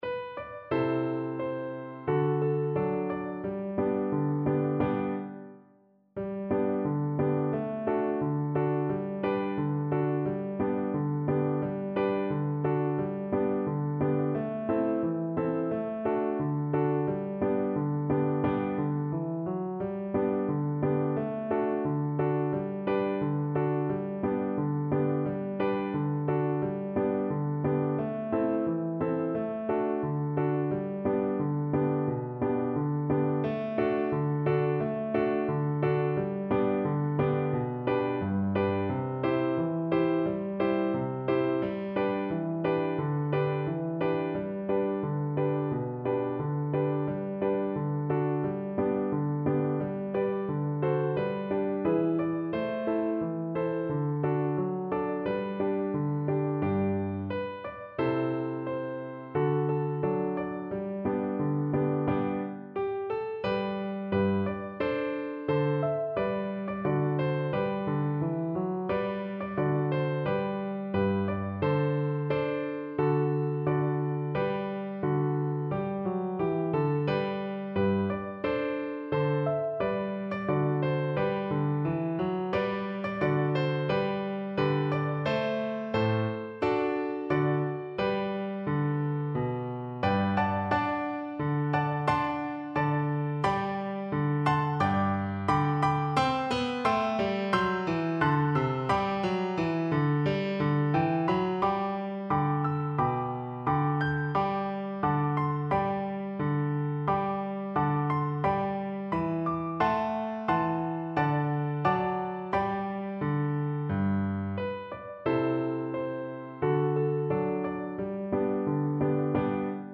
Andante =c.88